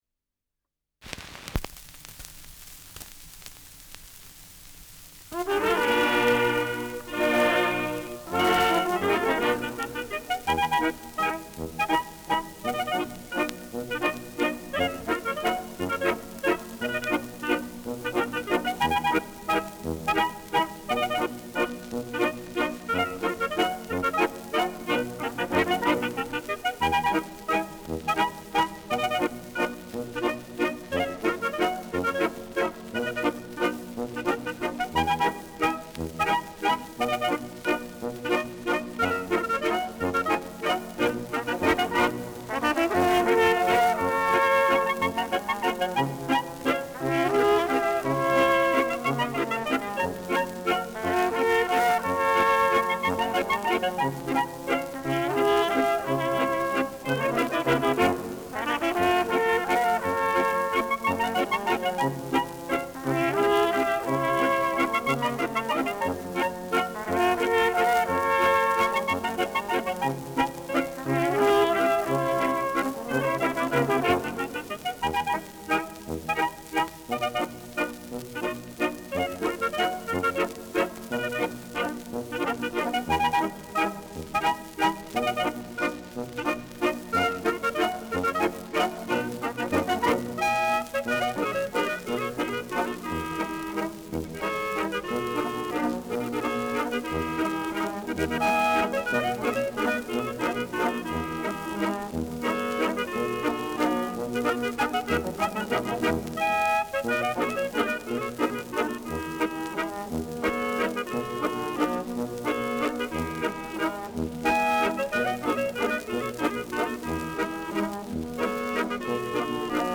Schellackplatte
Leicht abgespielt : Gelegentliches Knacken : Knistern